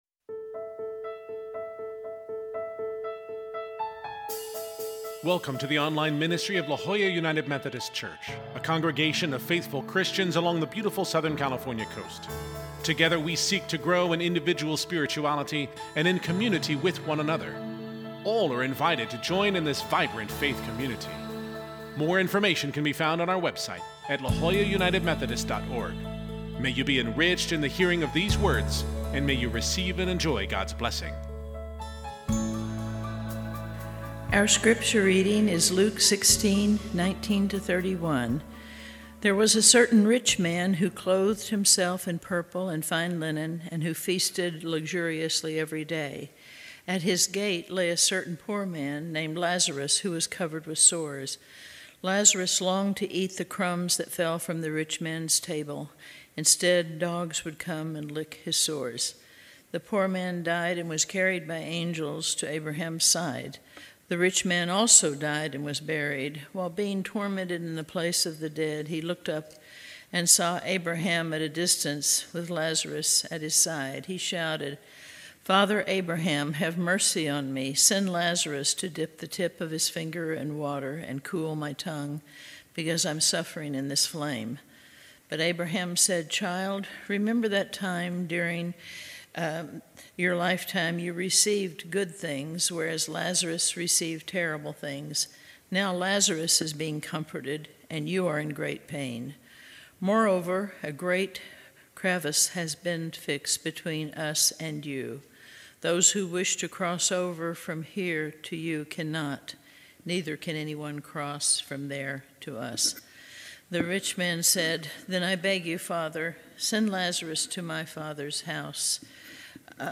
This Sunday we conclude our sermon series on the parables of Jesus.